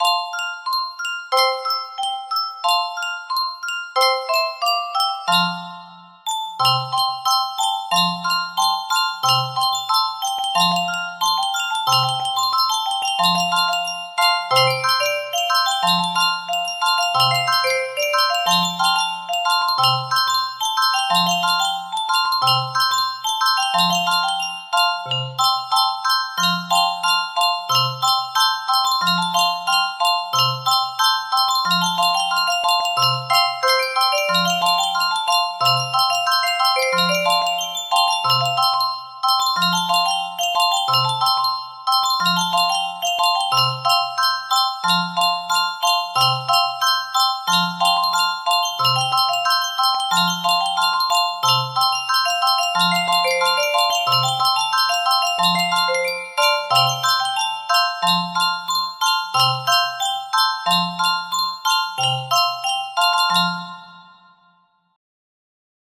Full range 60
lagu anak warisan wali songo